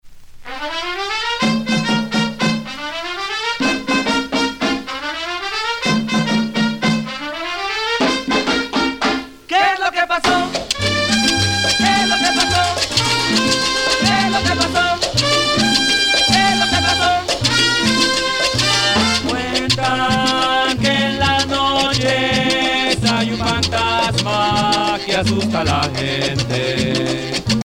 danse : pachanga (Cuba)
Pièce musicale éditée